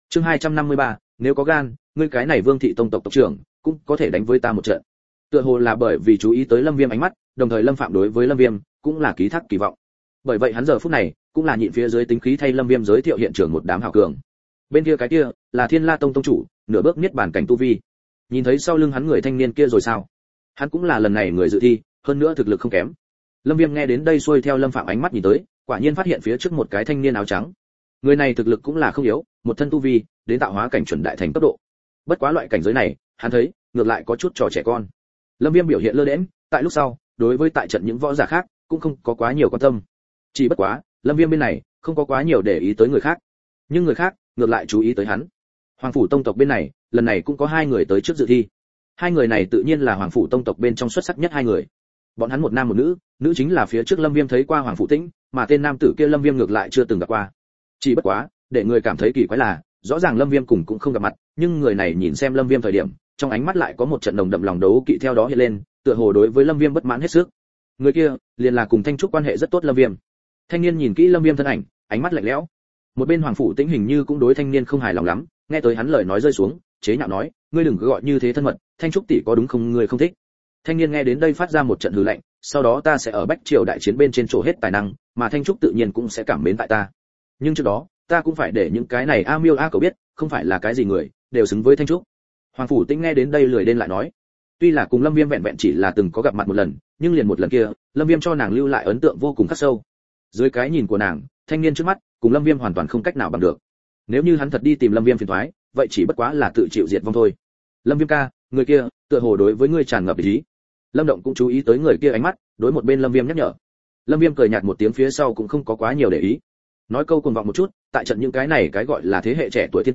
Nghe truyện Audio online Người Tại Võ Động, Bắt Đầu Đánh Dấu Dược Lão Giới Chỉ: Xuyên qua võ động, Lâm Viêm trước tiên tiến về Lâm gia hậu sơn ao nhỏ tìm kiếm